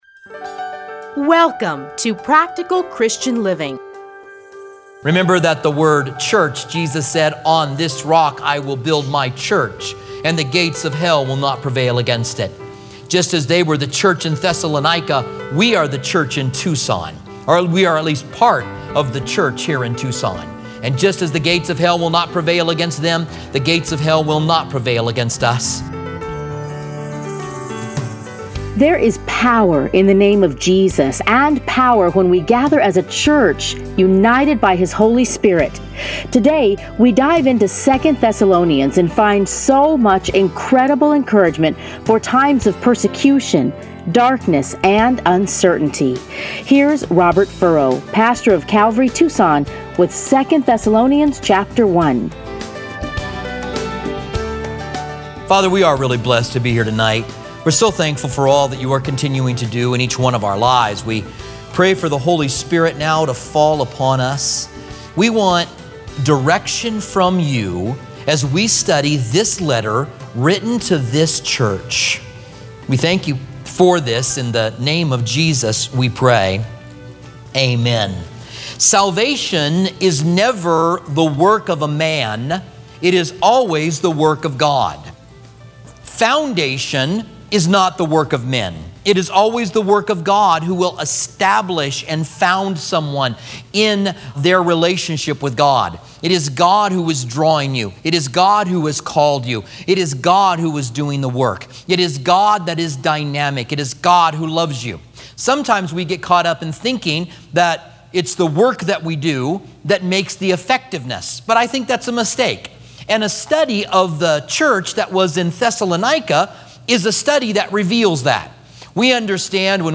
Listen here to a teaching from 2 Thessalonians.